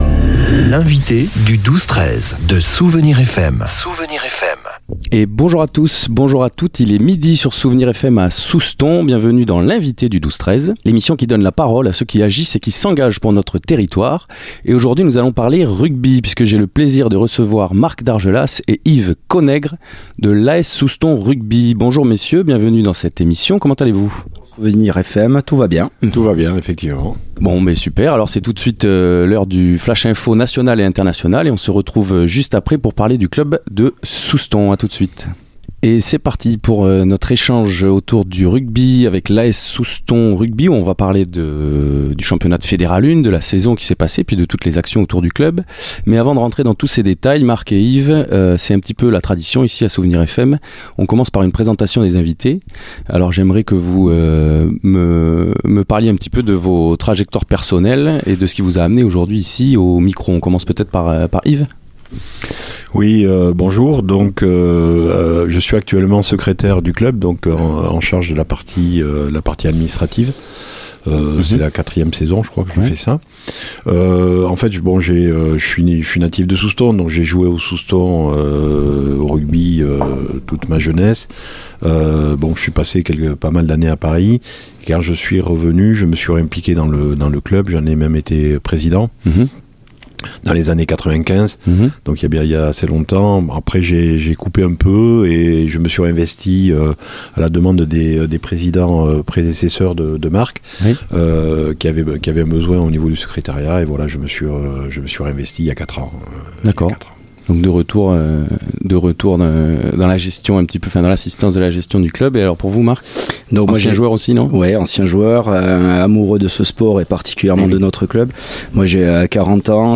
Pendant une heure d'échanges, les invités sont revenus sur une saison intense en Fédérale 1.